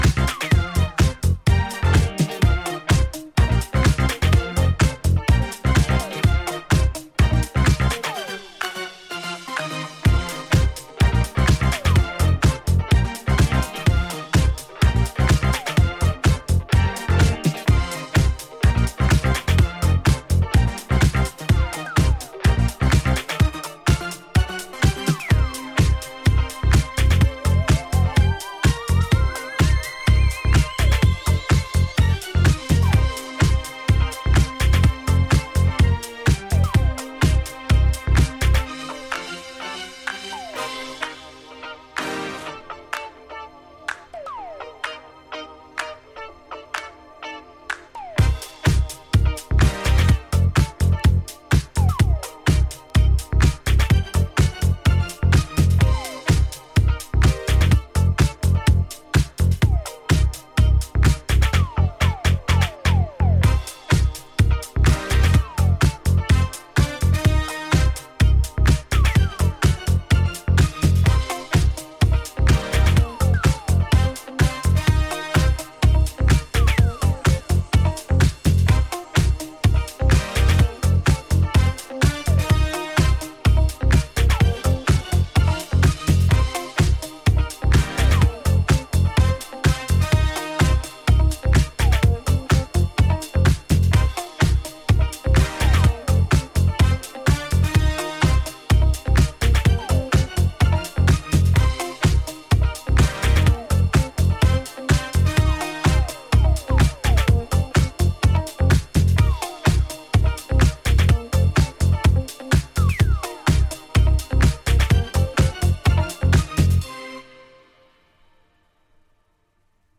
80'Sエレクトリック・ディスコ・ブギーでPOPなグルーヴ
ジャンル(スタイル) HOUSE / NU DISCO